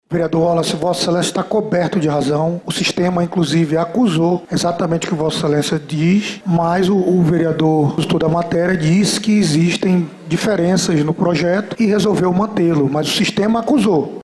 Em seguida, o presidente da Casa, vereador Caio André (PSC), confirmou a existência do PL. (Ouça)